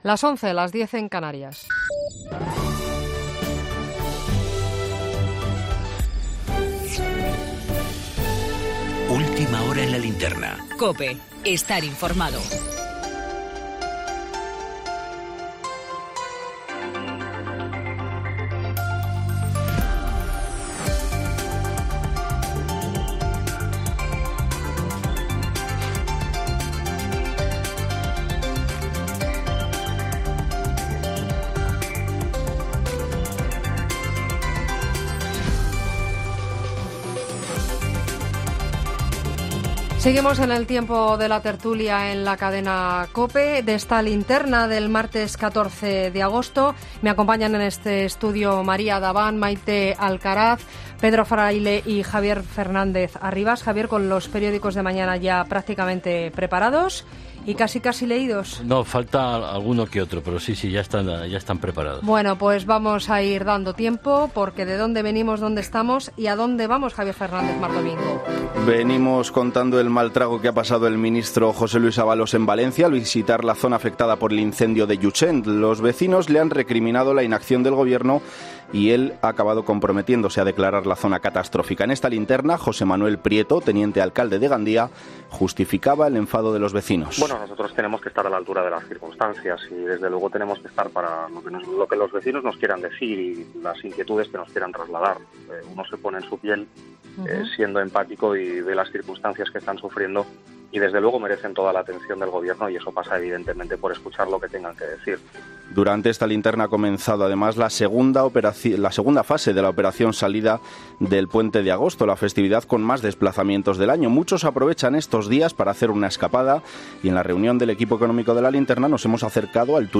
Tertulia II en ‘La Linterna’, martes 14 de agosto de 2018